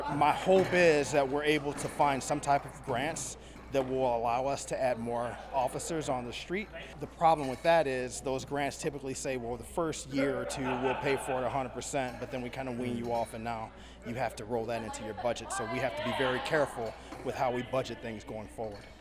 The Riley County Police Department held a town hall meeting Tuesday evening to inform citizens about their crime reduction plan.
RCPD Director Brian Peete spoke to the public about future goals he has for the department…